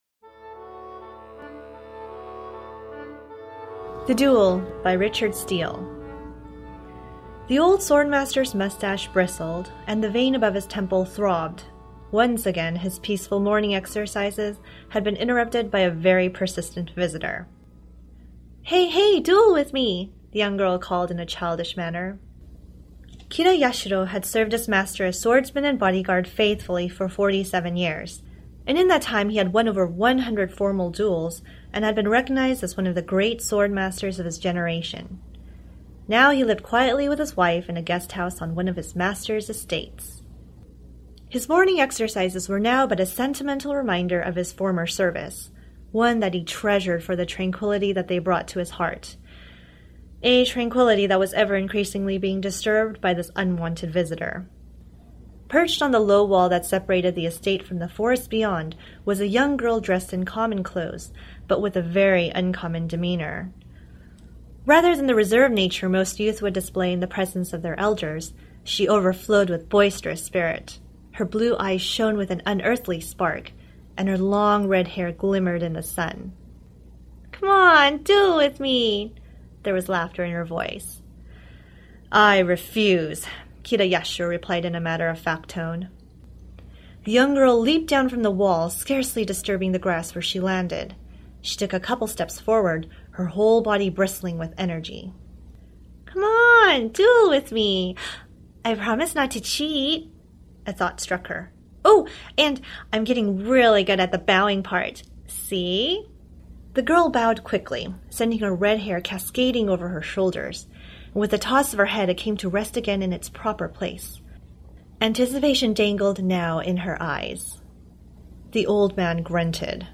A performance of the flash fiction story (approximately 6 minutes).